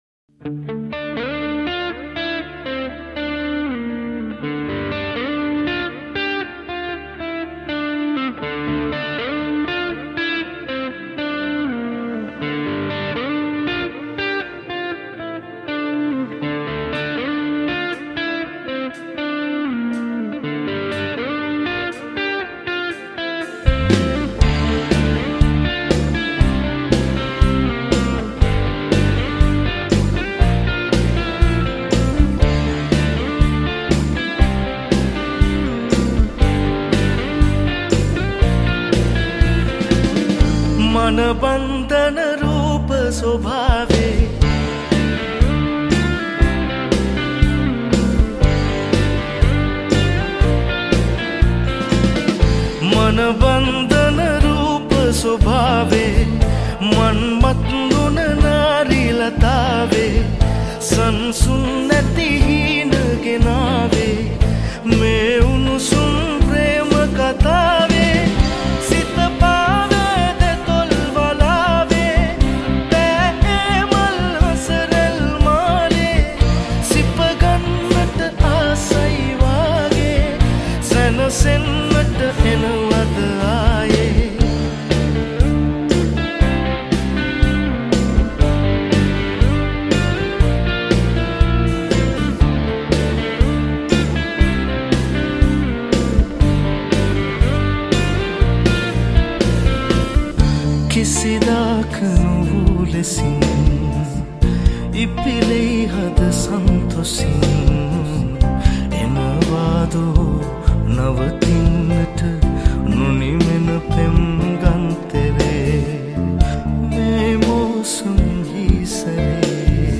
Original Sinhala Live Cover